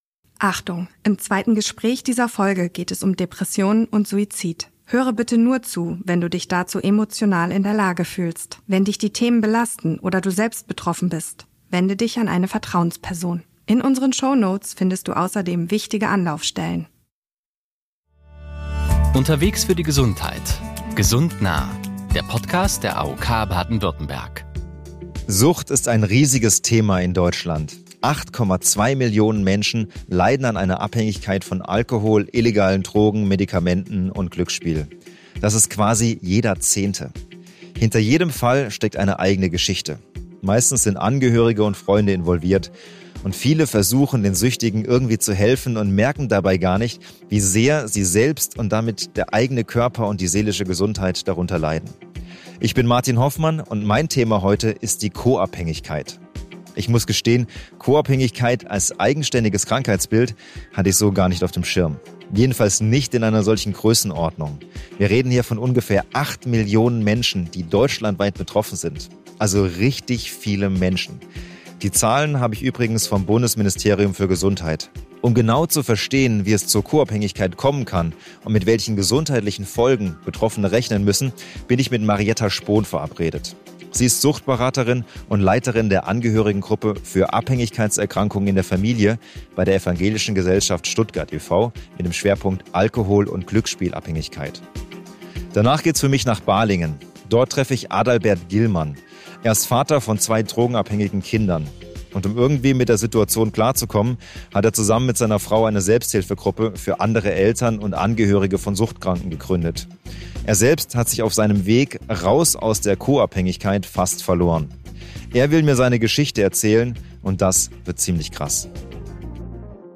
Ist eine Person suchtkrank, leidet oft auch ihr Umfeld. Eine Suchtberaterin und ein betroffener Vater berichten von ihren Erfahrungen.